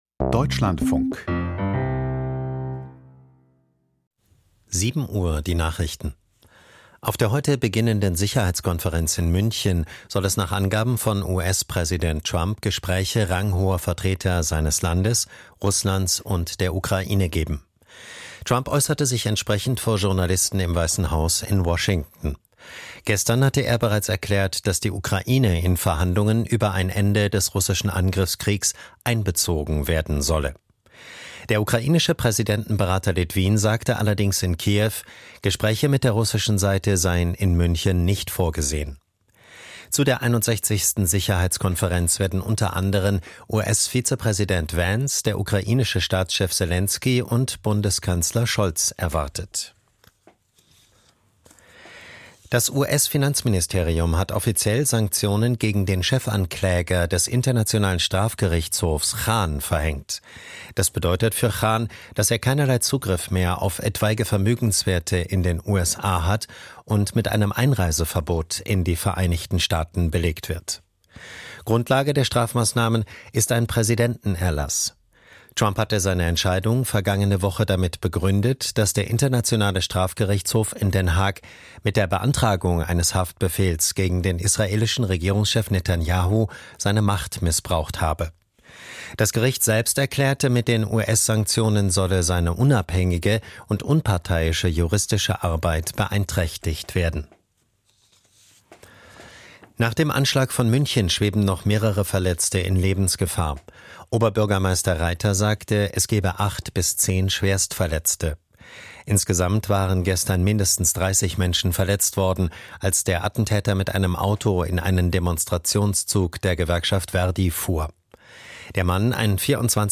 Ukraine-Krieg: Interview zum US-Vorstoß mit Norbert Röttgen, CDU - 14.02.2025